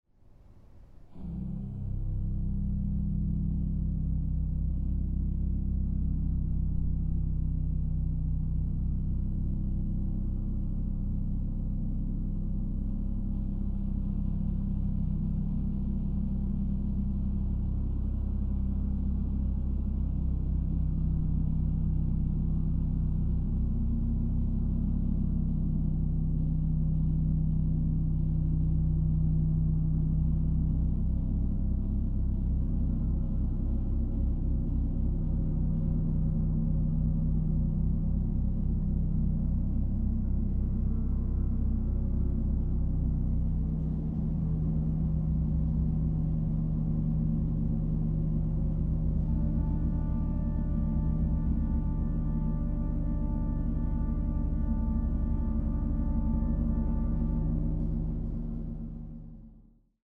Organ
Percussion
Gong
Recording: Het Orgelpark, Amsterdam, 2023